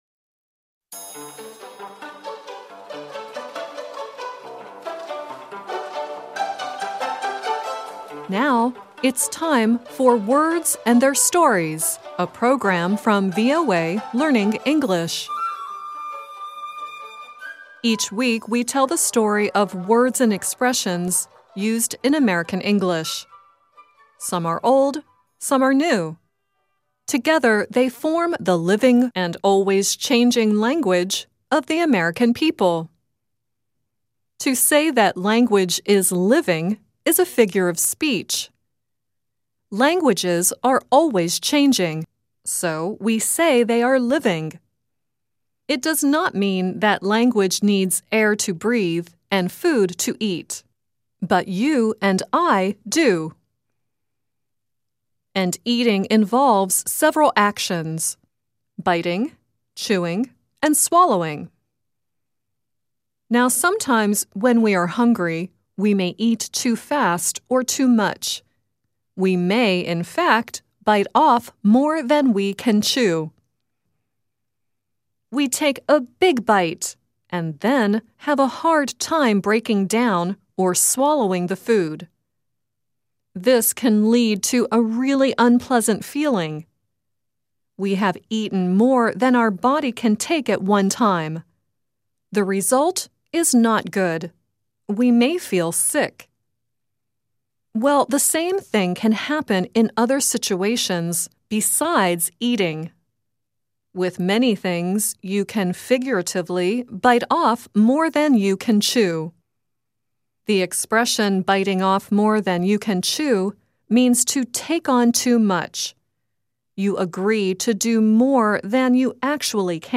The song at the end is Suzi Quatro singing "I Bit Off More Than I Could Chew."